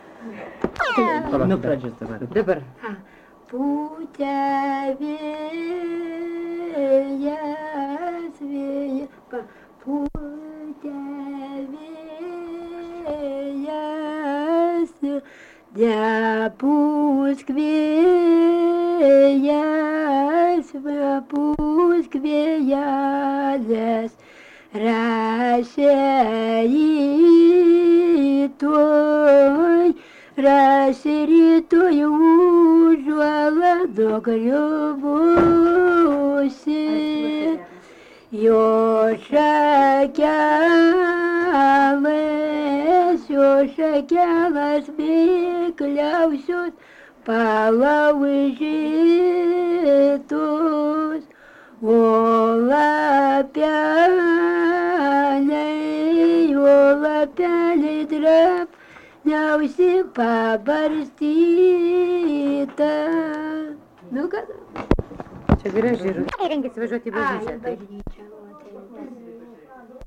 Dalykas, tema daina
Erdvinė aprėptis Čižiūnai (Varėna)
Atlikimo pubūdis vokalinis